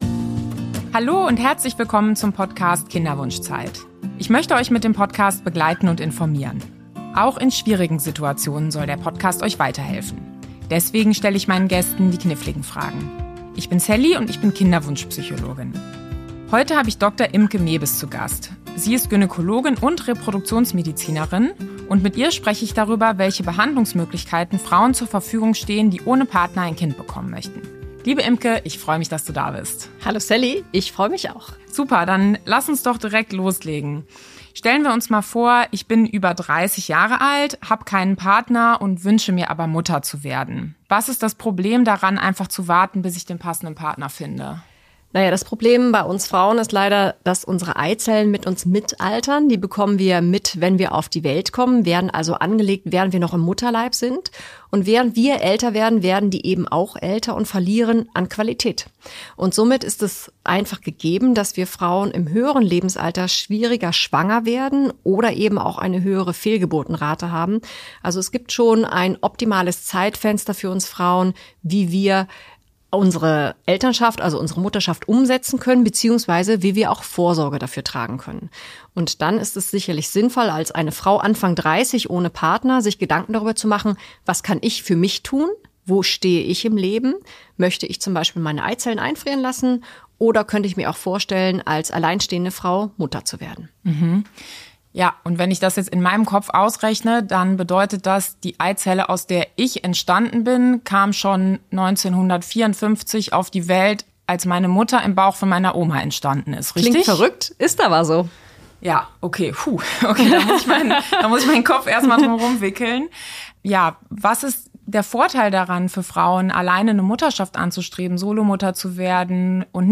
Zu Wort kommen Fachleute aus der Medizin, der Beratung und auch Betroffene.